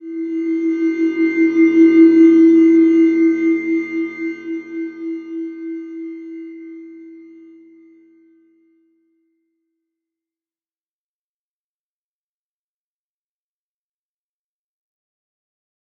Slow-Distant-Chime-E4-f.wav